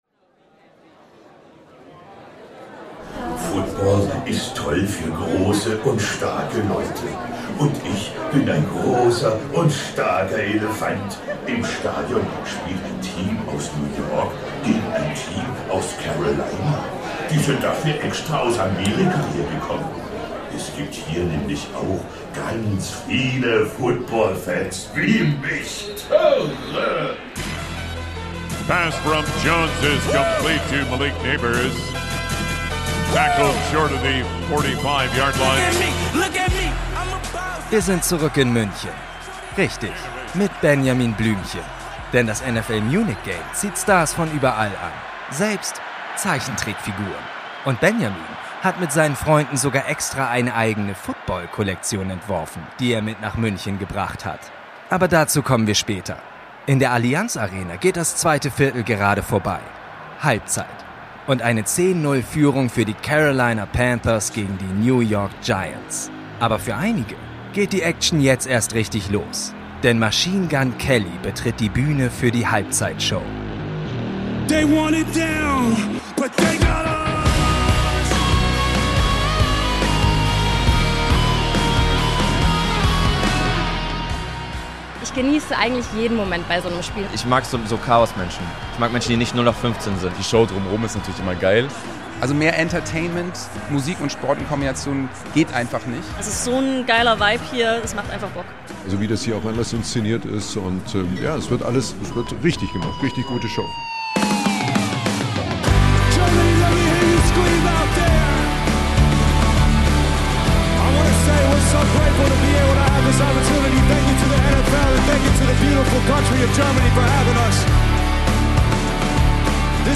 Und während über 70.000 Menschen gemeinsam “Country Roads” singen, tauchen wir in die Geschichte der Halftime-Shows ein. Woher haben die Halftime-Shows ihren Ursprung?
Und auch wir spulen nochmal zurück: Einen Tag vor dem Spiel in München sind wir backstage bei der Probe von MGK und hören … erstmal nichts.